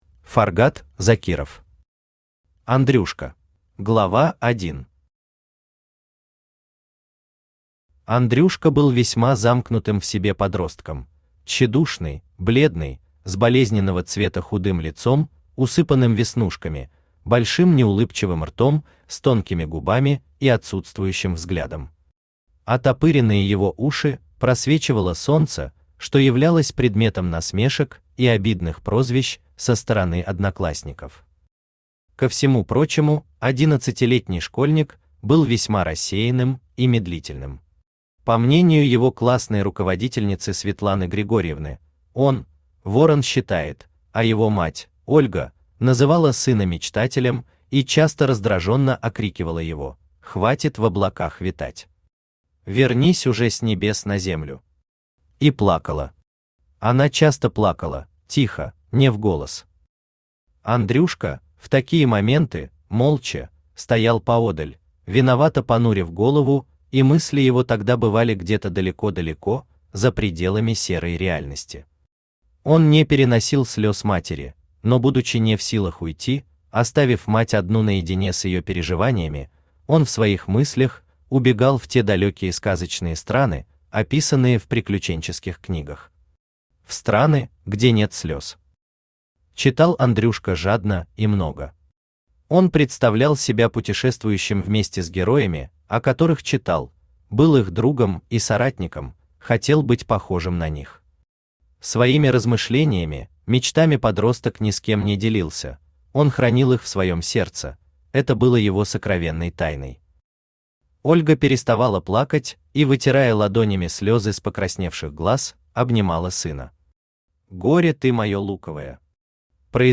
Аудиокнига Андрюшка | Библиотека аудиокниг
Aудиокнига Андрюшка Автор Фаргат Закиров Читает аудиокнигу Авточтец ЛитРес.